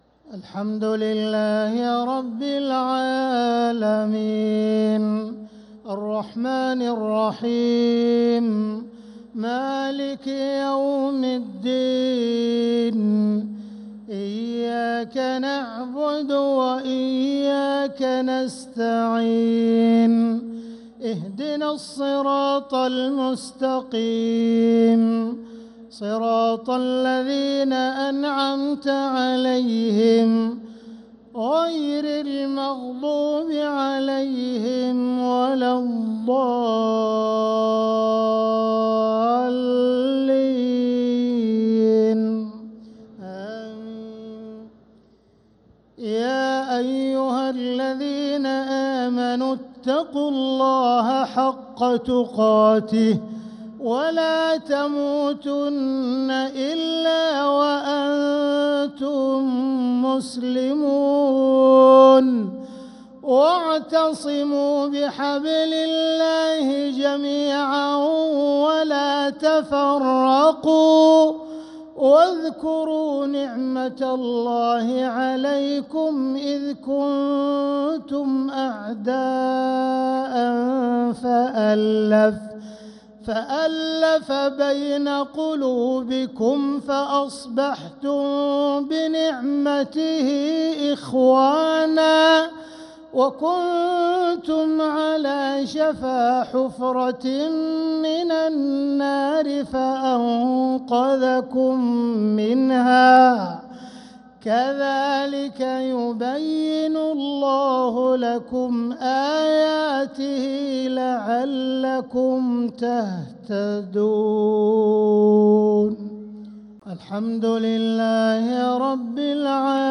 عشاء الجمعة 7-9-1446هـ من سورة آل عمران 102-105 | Isha prayer from Surat Al-Imran 7-3-2025 > 1446 🕋 > الفروض - تلاوات الحرمين